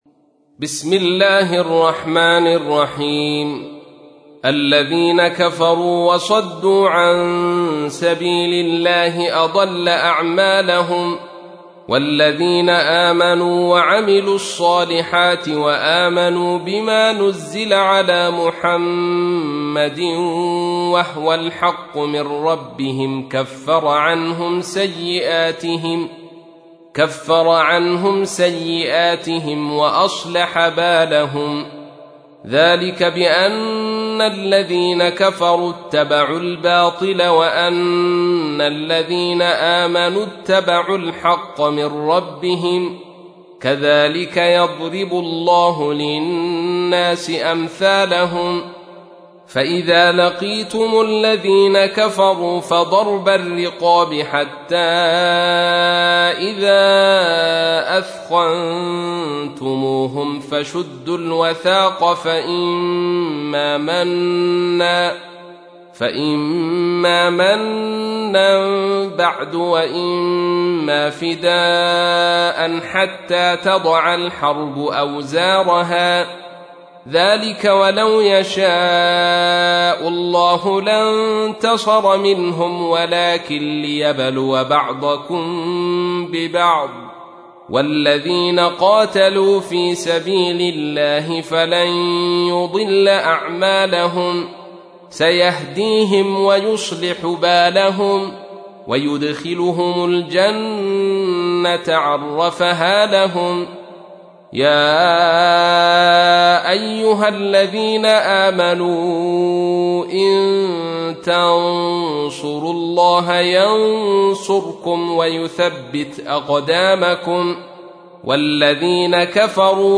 تحميل : 47. سورة محمد / القارئ عبد الرشيد صوفي / القرآن الكريم / موقع يا حسين